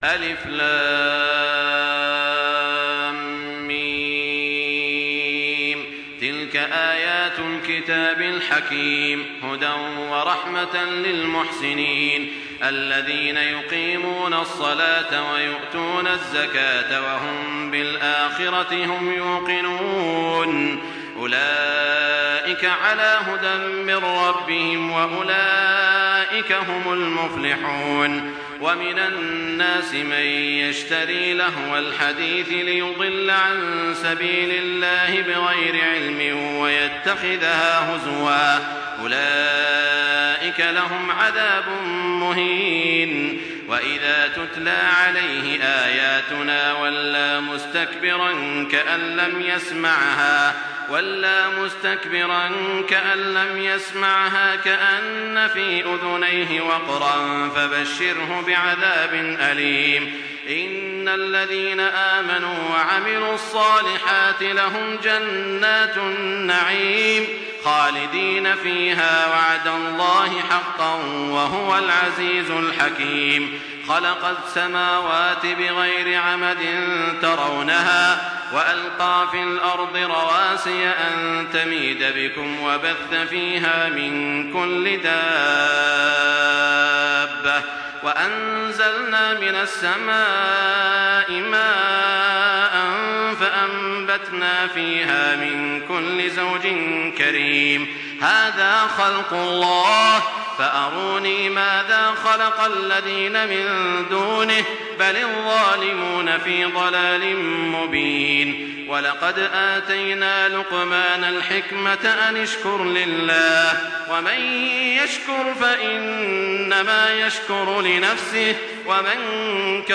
Surah লুক্বমান MP3 by Makkah Taraweeh 1424 in Hafs An Asim narration.